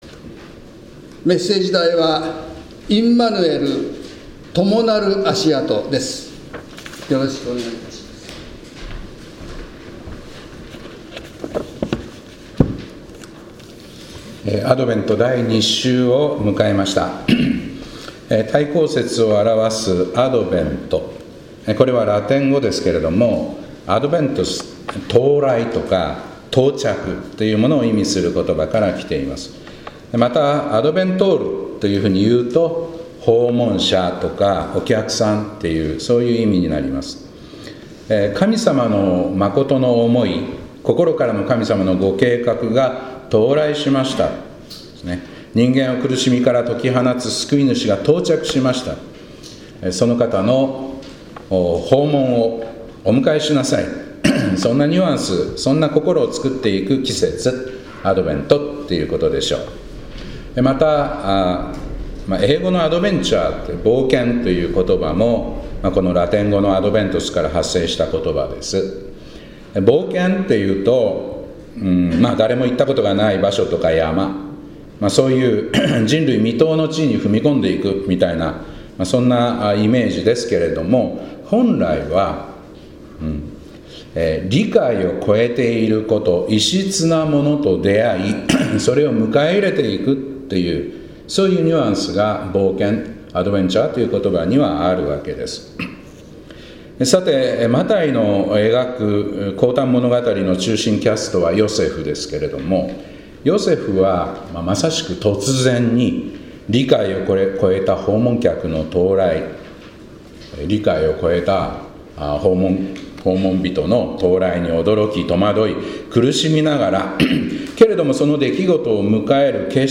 2024年12月8日礼拝「インマヌエル・共なるあしあと」